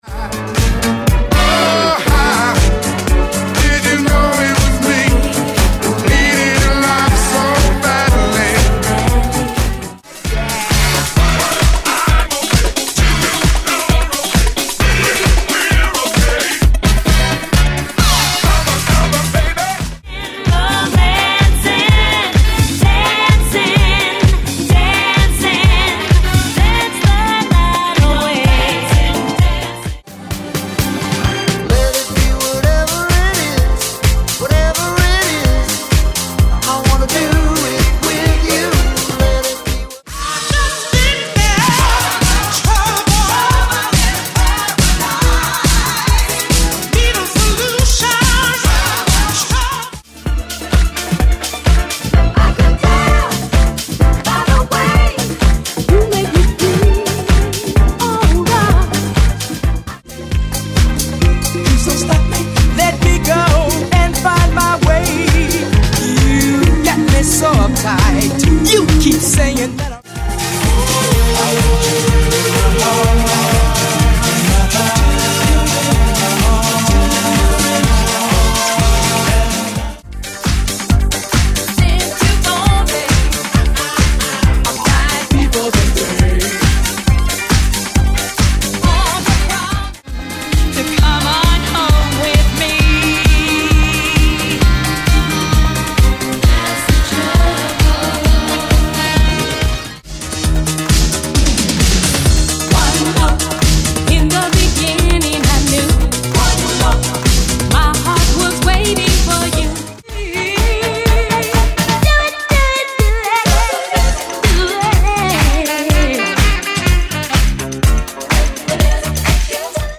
Genre: 80's